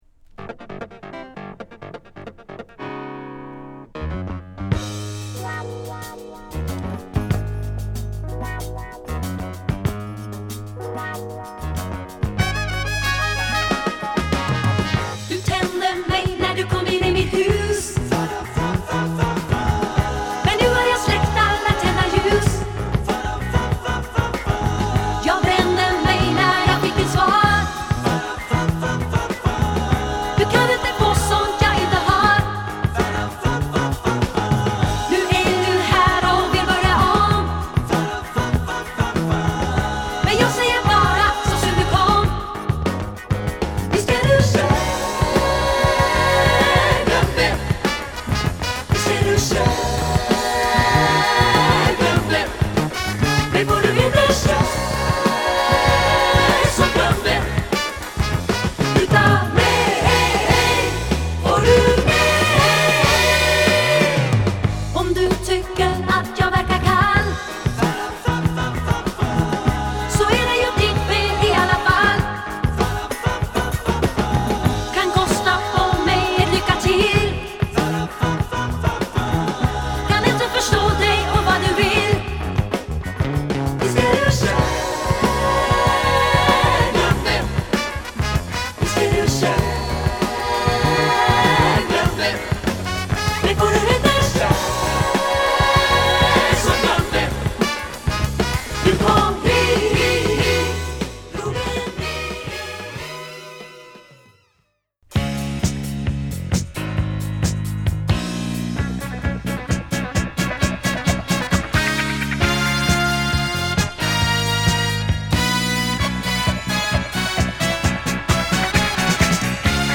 ソウル／ポップ的なサウンドが強くなっての本作。